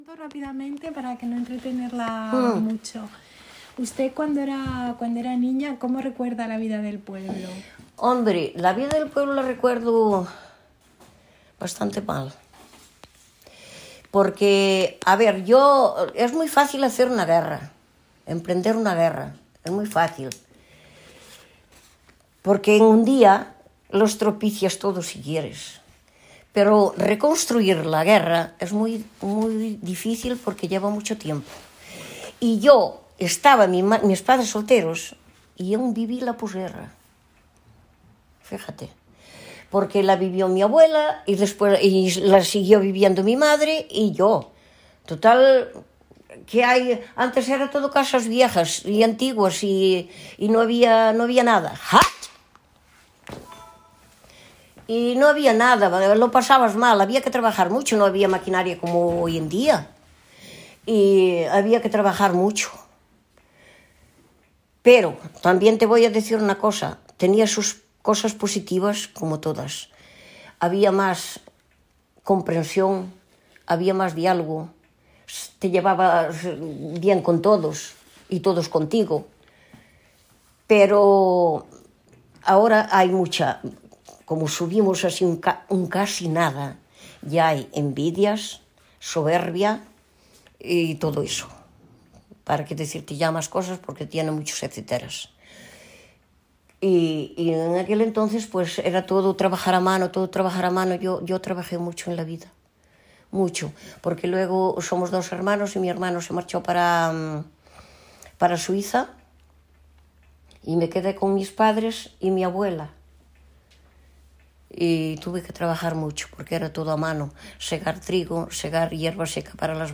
Locality Brandeso (Arz�a)
Informants I1:�mujer